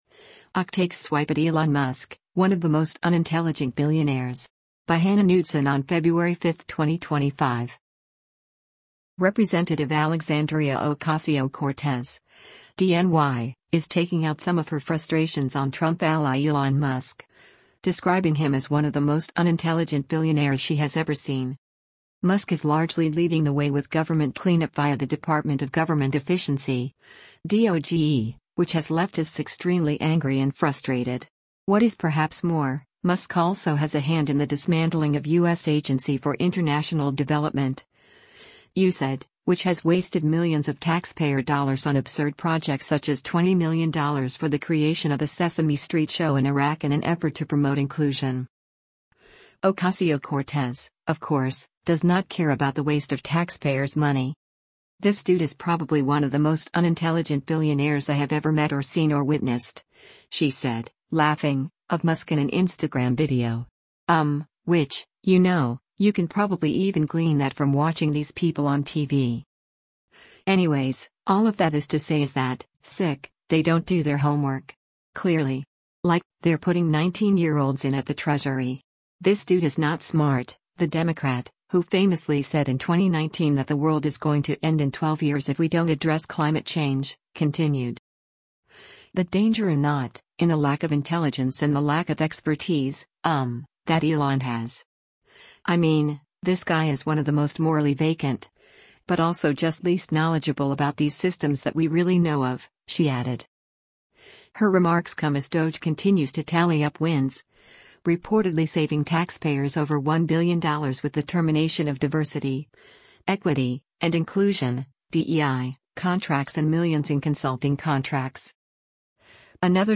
“This dude is probably one of the most unintelligent billionaires I have ever met or seen or witnessed,” she said, laughing, of Musk in an Instagram video.